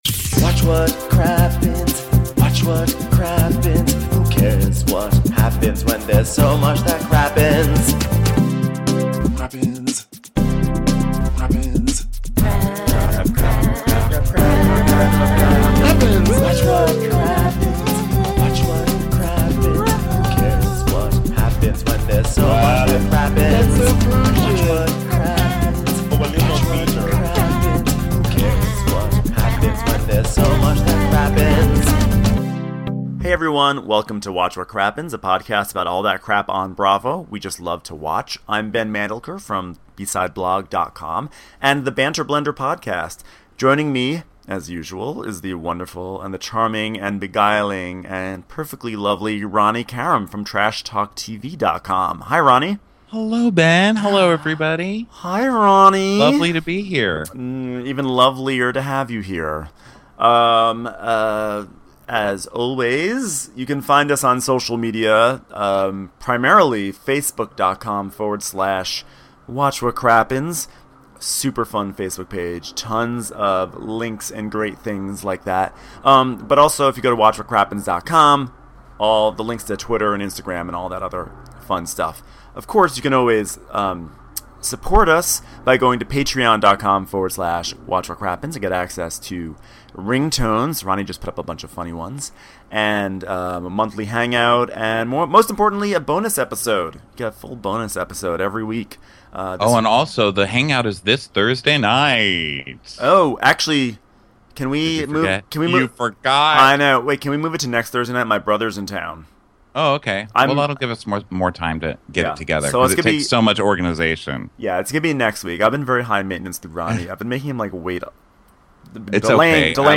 It's a foreign accent extravaganza.